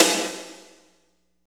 49.03 SNR.wav